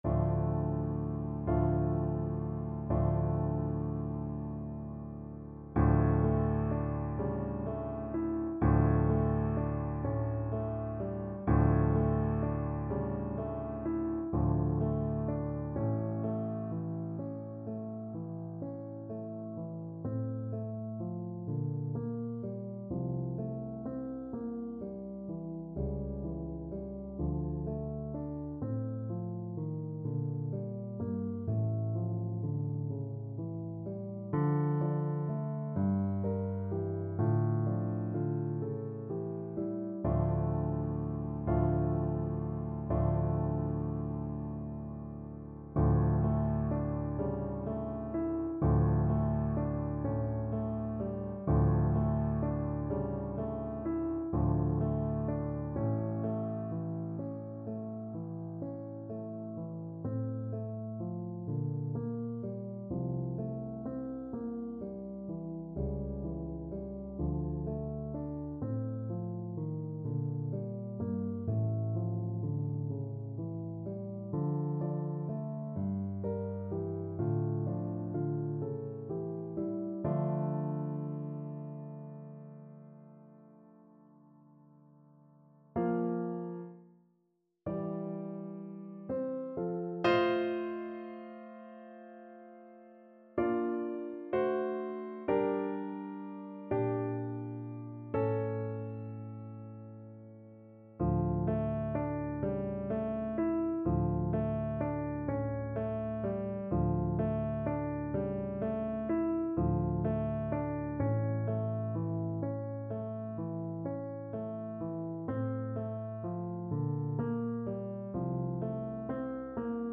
Play (or use space bar on your keyboard) Pause Music Playalong - Piano Accompaniment Playalong Band Accompaniment not yet available reset tempo print settings full screen
D minor (Sounding Pitch) A minor (French Horn in F) (View more D minor Music for French Horn )
6/8 (View more 6/8 Music)
= 42 Andante con moto (View more music marked Andante con moto)
Classical (View more Classical French Horn Music)